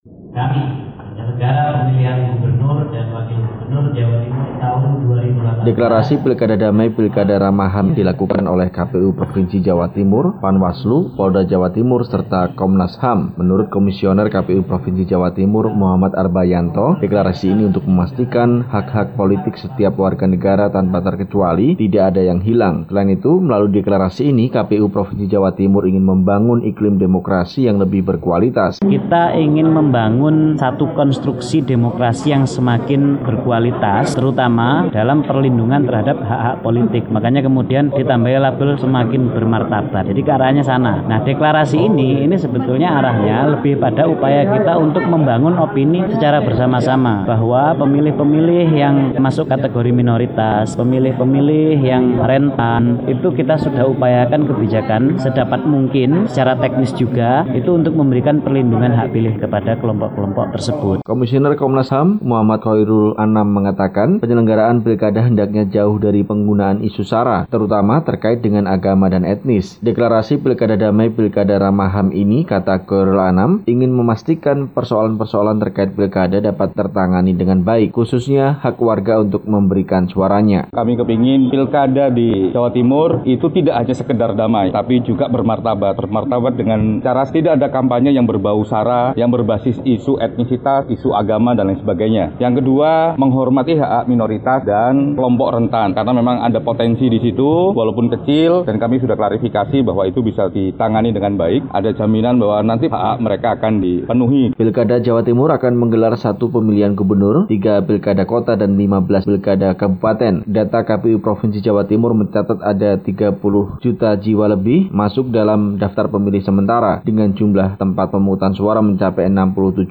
melaporkan dari Surabaya, Jawa Timur.